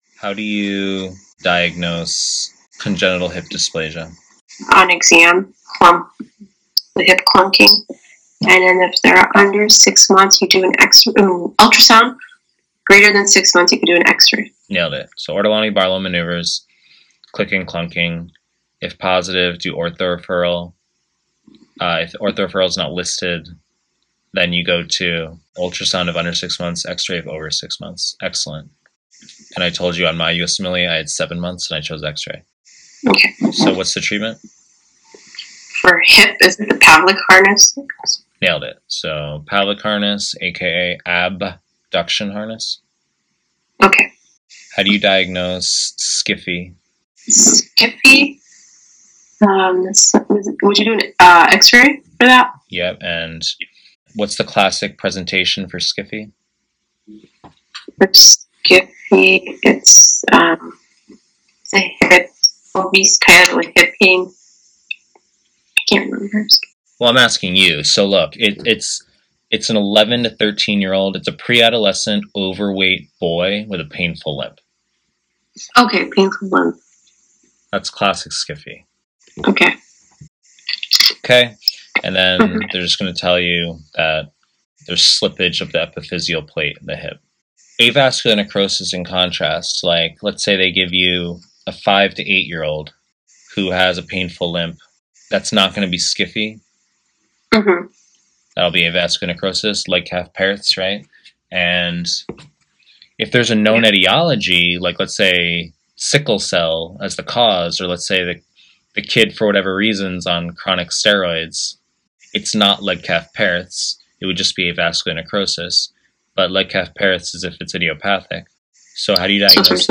Pre-recorded lectures